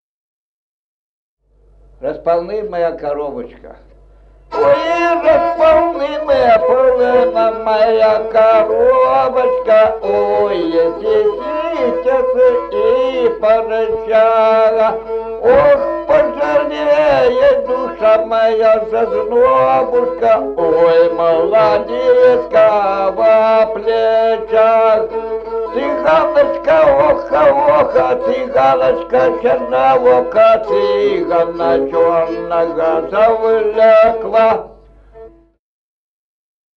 Музыкальный фольклор села Мишковка «Коробочка», репертуар скрипача.